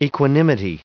1478_equanimity.ogg